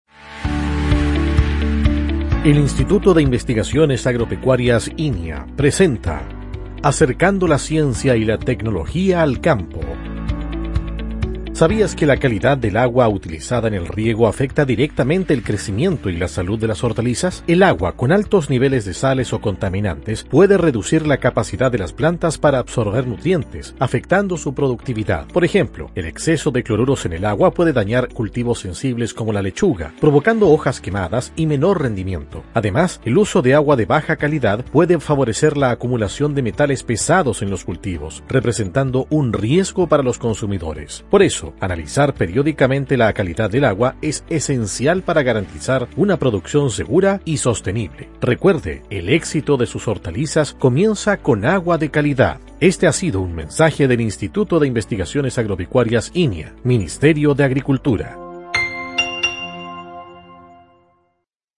Cápsula Radial INIA Remehue